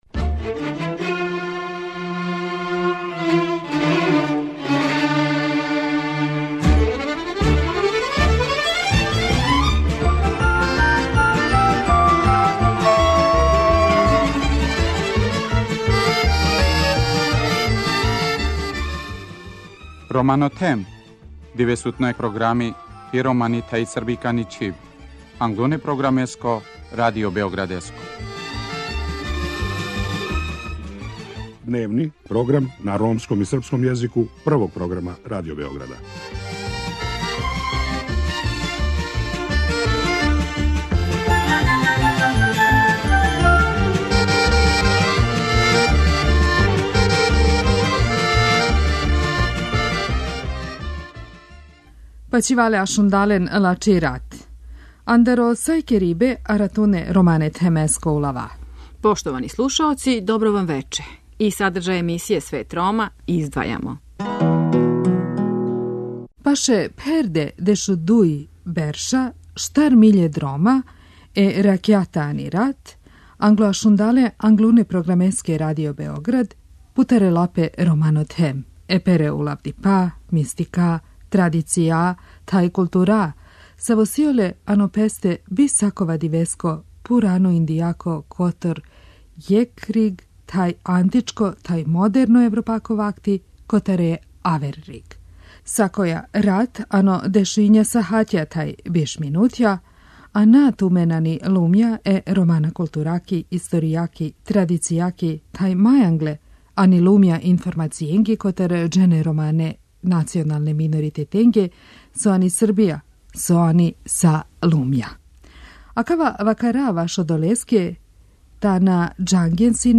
Осим великог броја нумера ромских извођача који су познати скоро свима, у Свету Рома слушаоци имају прилике да чују и музику ромских и других извођача коју, осим код нас, немају прилике било где друго да чују.